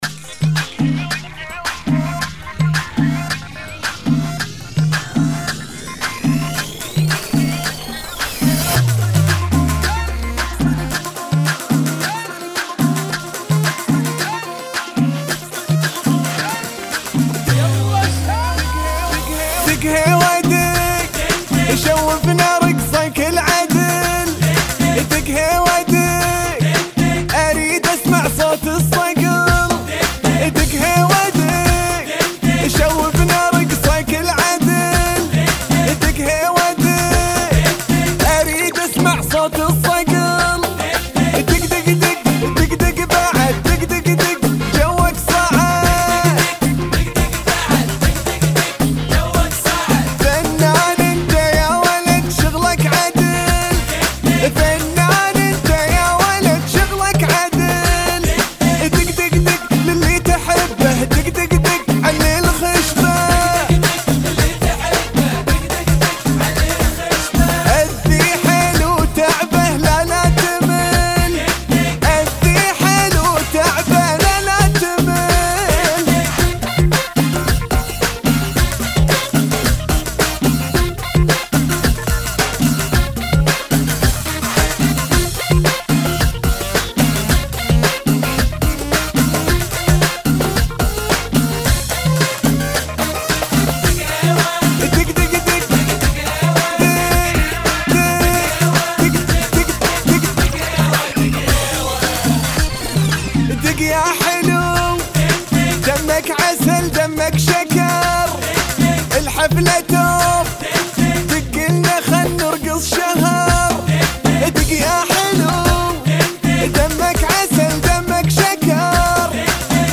(110 BMP)